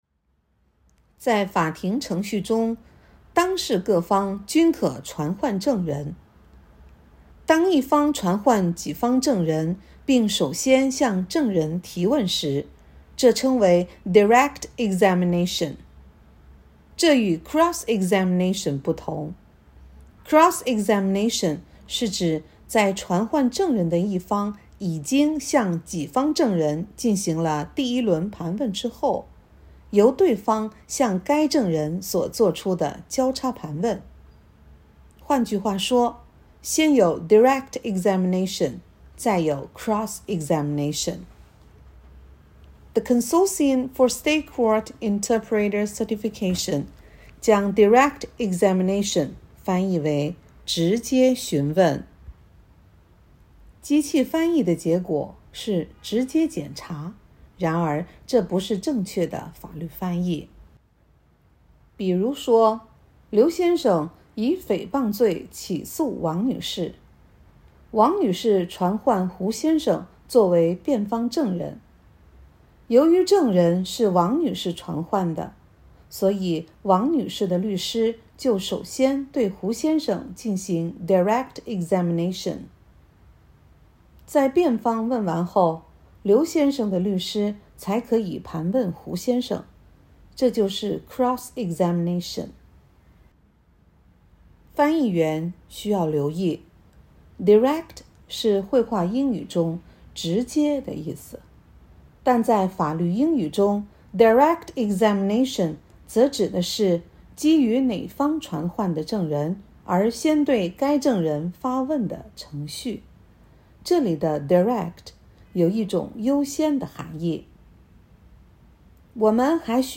Direct examination. 0816.mp3